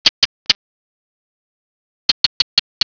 gecko.wav